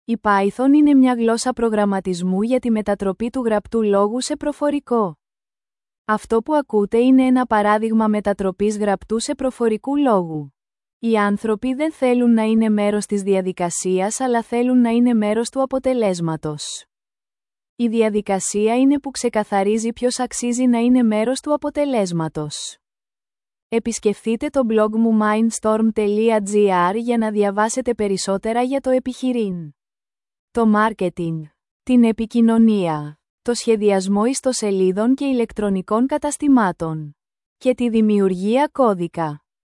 Ακούστε το αρχείο welcome_2.mp3 – Γυναικεία φωνή